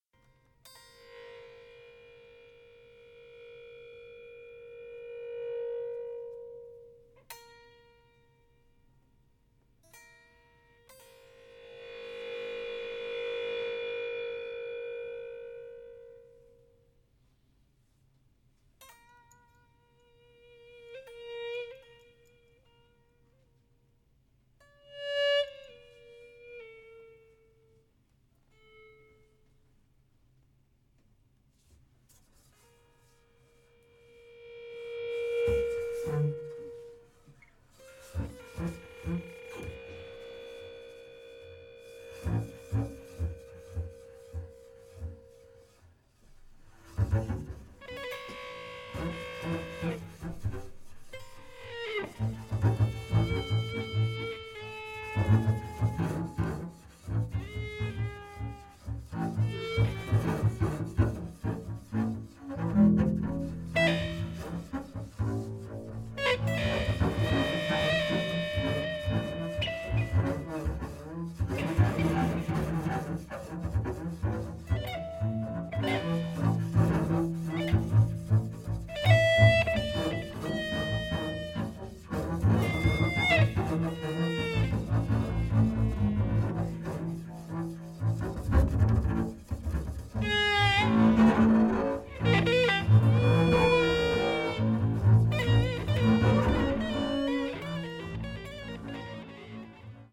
electric guitar
double bass
Recorded live
at "19PaulFort", Paris, France.